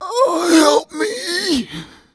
PAINHANG6.WAV